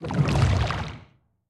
Sfx_creature_bruteshark_swim_slow_02.ogg